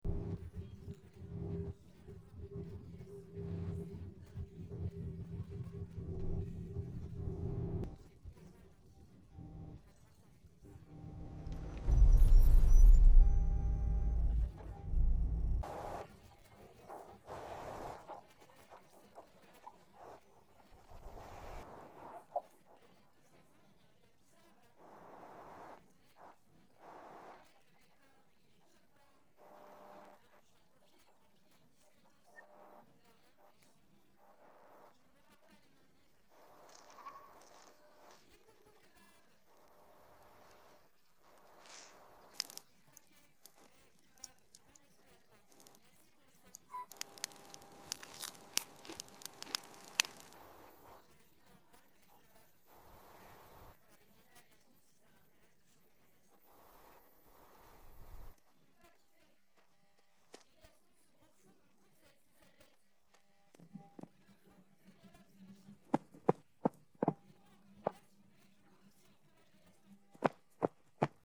Pozadí hudba